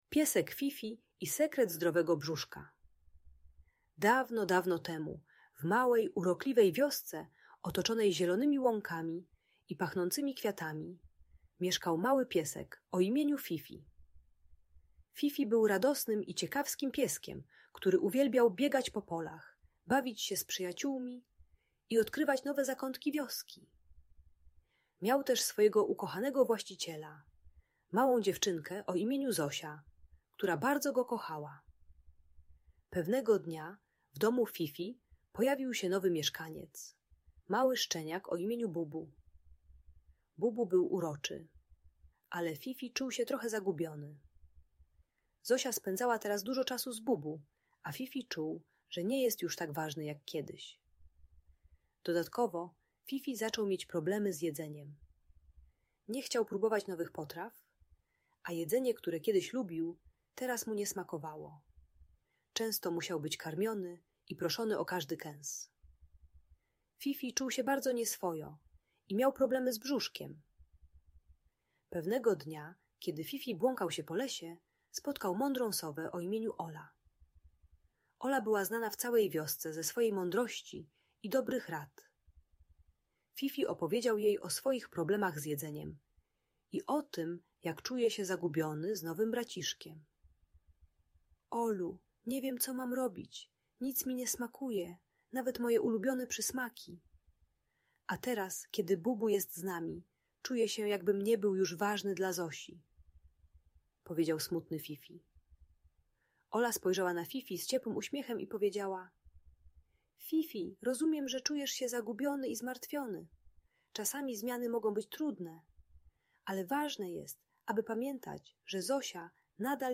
Historia Pieska Fifi i Sekretu Zdrowego Brzuszka - Audiobajka